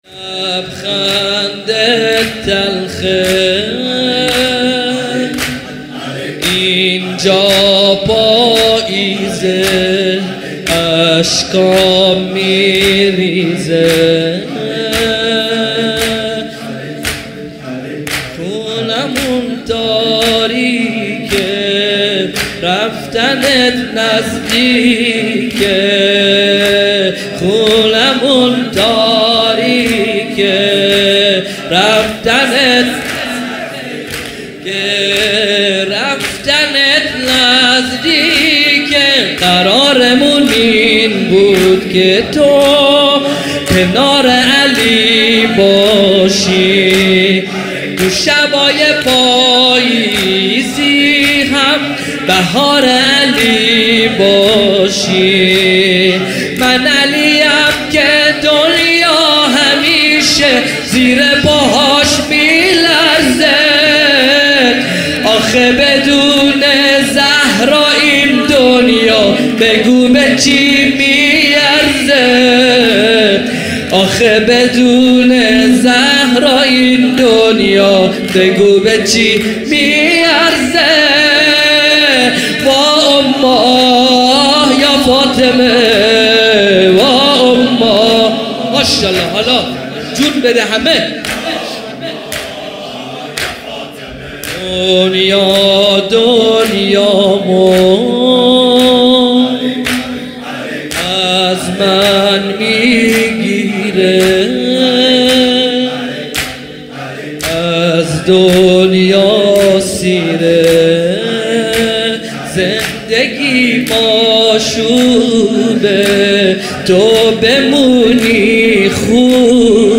مداحی
مراسم عزاداری شب های دهه فاطميه ١٤٣٩هجری قمری